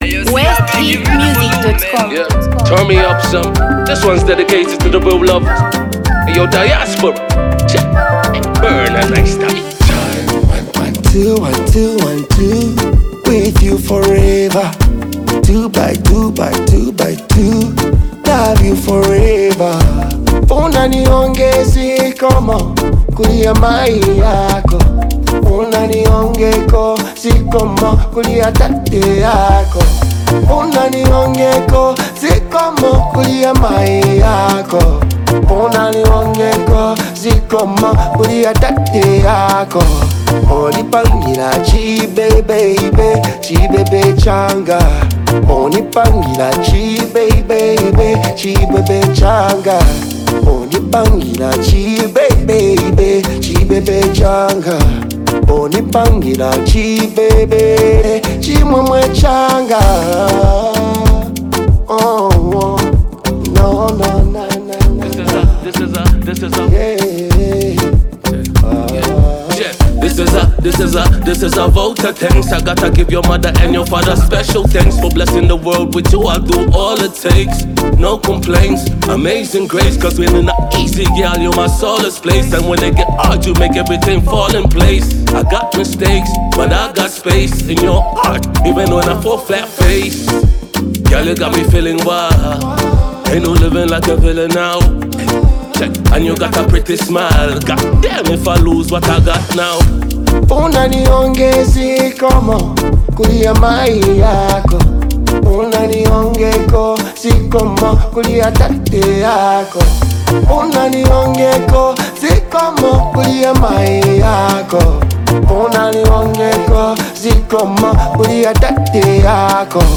Zambia Music
With its catchy rhythm and engaging lyrics